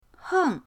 heng4.mp3